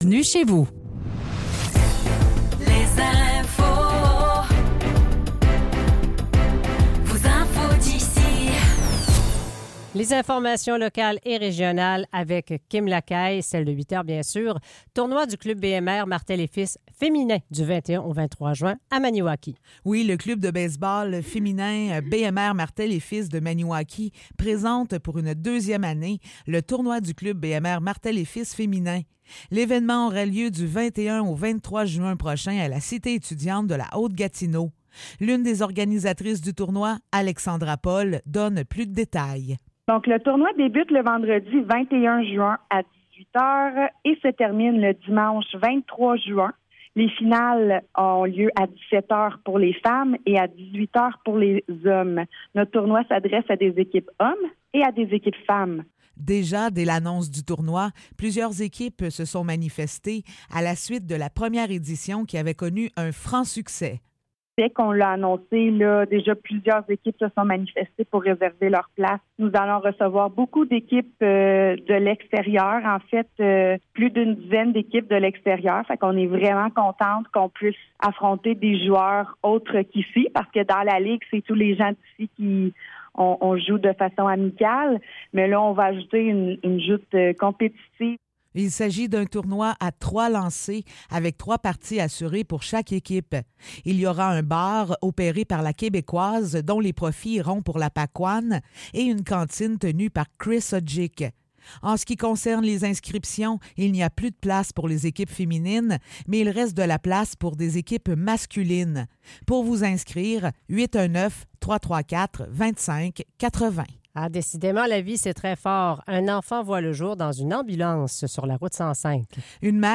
Nouvelles locales - 16 mai 2024 - 8 h